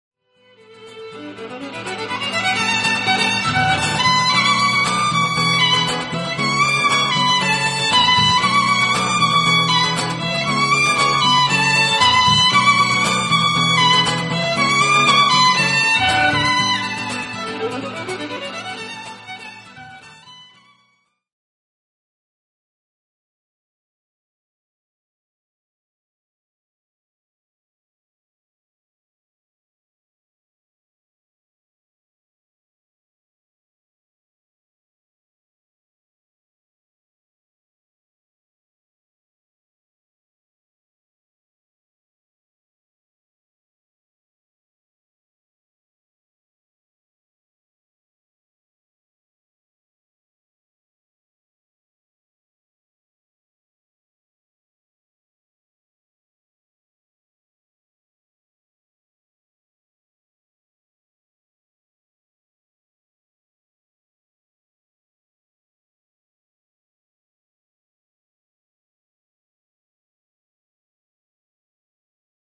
violin & guitar duets